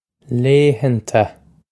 laethanta lay-han-ta
Pronunciation for how to say
lay-han-ta
This is an approximate phonetic pronunciation of the phrase.